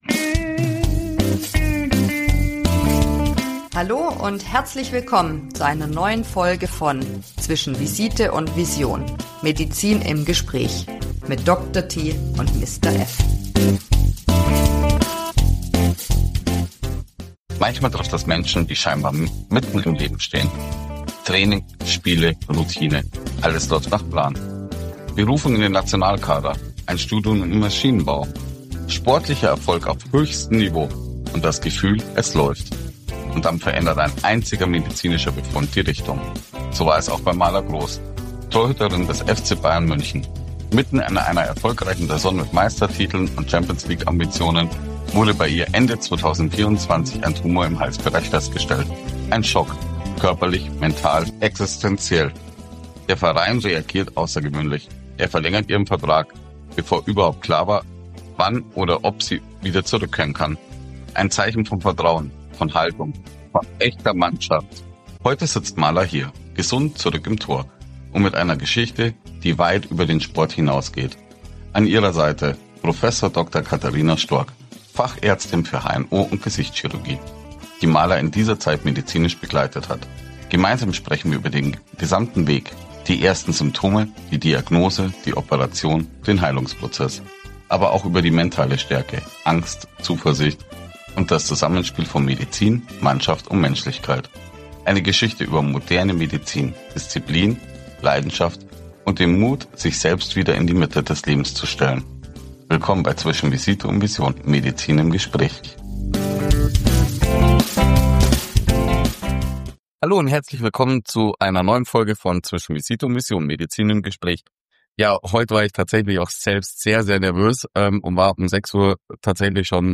Beschreibung vor 5 Monaten In dieser Episode sprechen wir mit Maria Luisa „Mala“ Grohs, Torhüterin des FC Bayern München, über ihre persönliche Geschichte mit der Diagnose eines Tumors im Halsbereich.